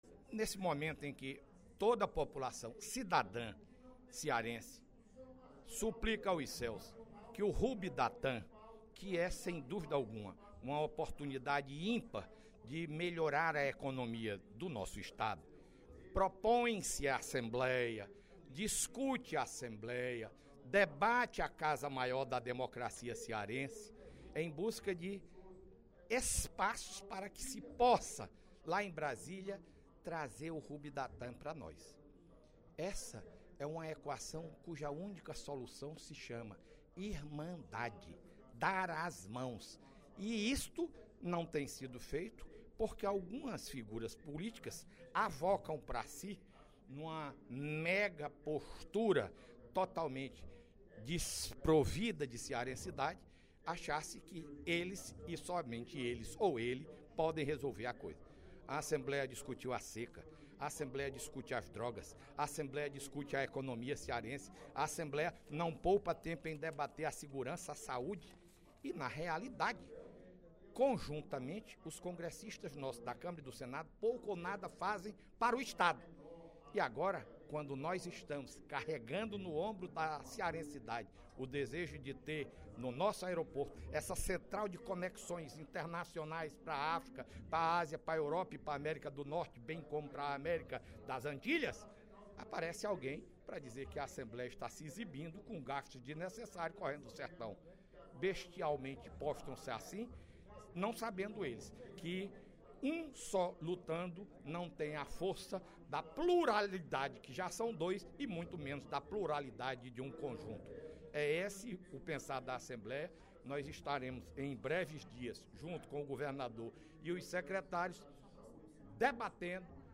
Em aparte, o presidente da Assembleia, deputado Zezinho Albuquerque (Pros), enfatizou que a Casa vai continuar encampando mobilizações dos mais diversos temas que sejam do interesse da população cearense.